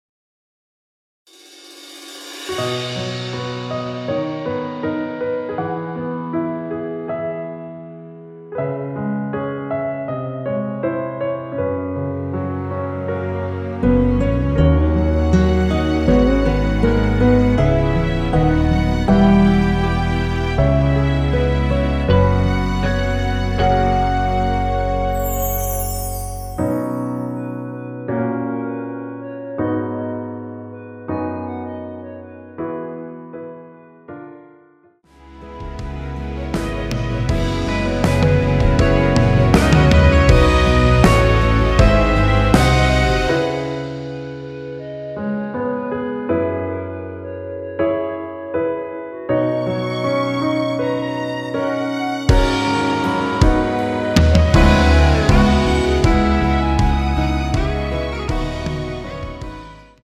원키에서(-2)내린 멜로디 포함된 (1절+후렴) MR입니다.
앞부분30초, 뒷부분30초씩 편집해서 올려 드리고 있습니다.